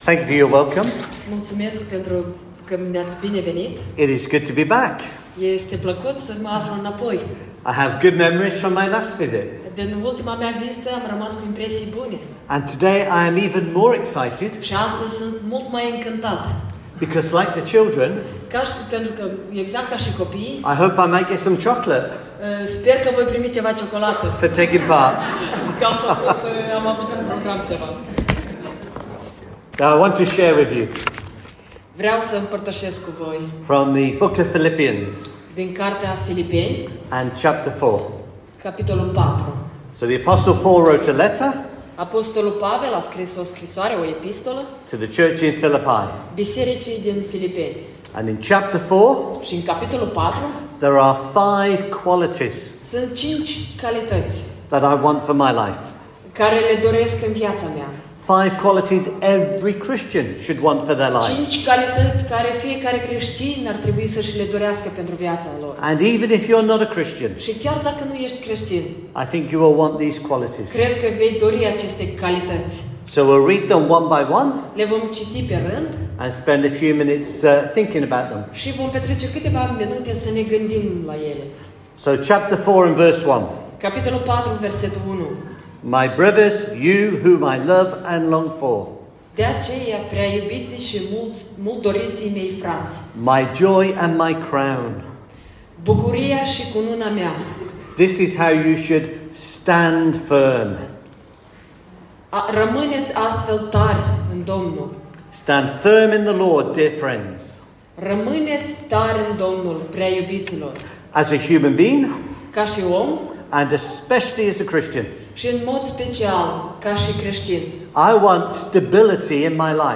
Meeting #1: a traditional house meeting with folk squeezed into a conservatory. lots of singing by the ladies of the Church and a good response to the gospel.